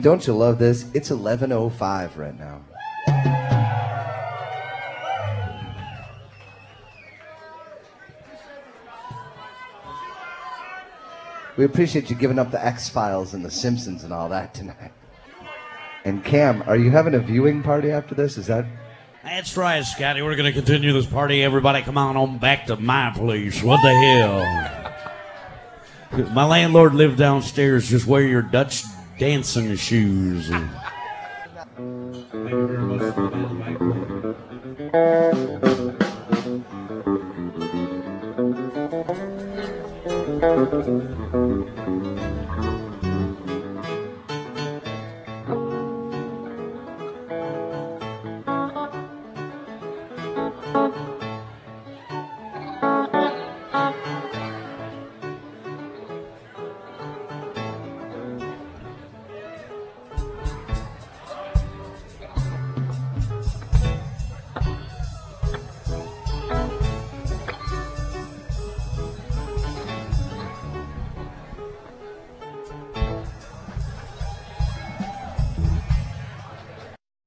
Show #263 - Sun, Dec 19, 1999 at Martyrs', Chicago, IL